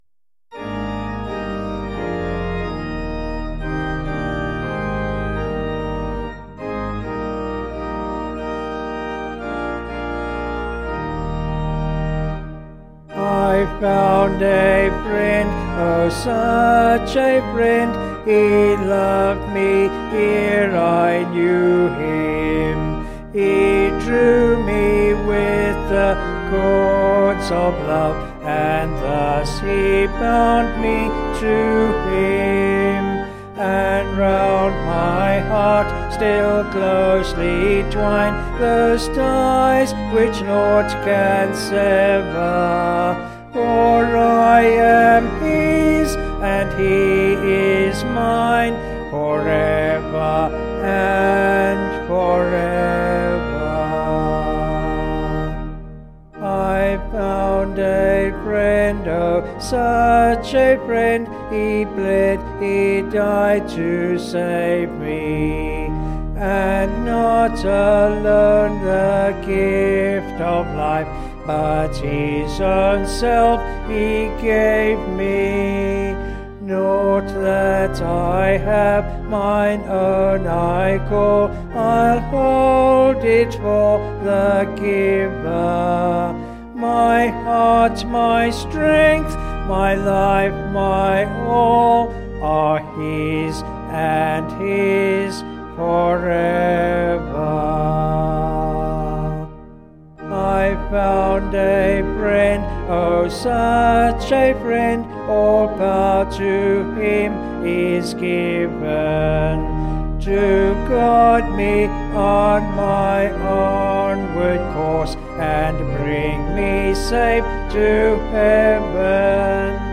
Vocals and Organ   265.7kb Sung Lyrics